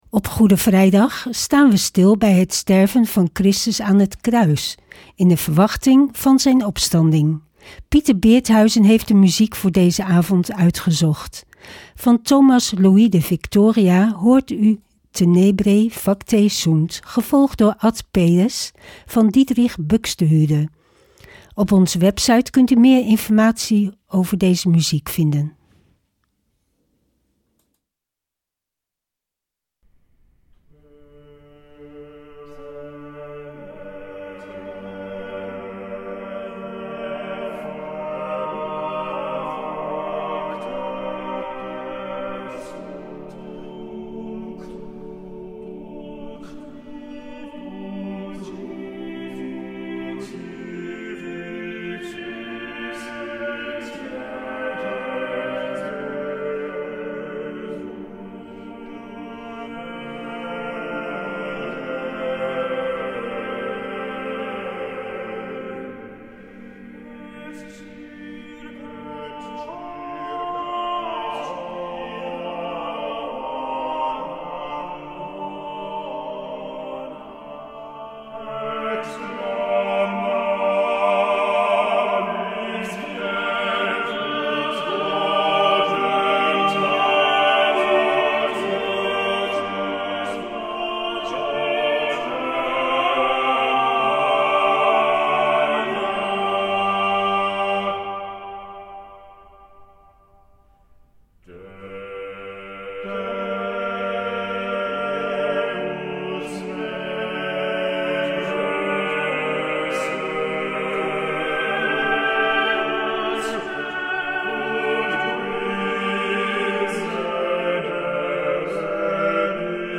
Opening op deze Goede Vrijdag met muziek, rechtstreeks vanuit onze studio.